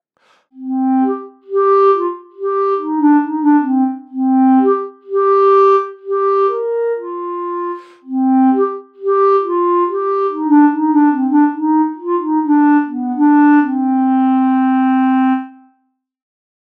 Klarnet
Powstaje miękki i soczysty dźwięk, którego charakterystyczną cechą jest łatwość zlewania się z barwą innych instrumentów.
Dźwięki instrumentów są brzmieniem orientacyjnym, wygenerowanym w programach:
Kontakt Native Instruments (głównie Factory Library oraz inne biblioteki) oraz Garritan (Aria Player).
Klarnet.mp3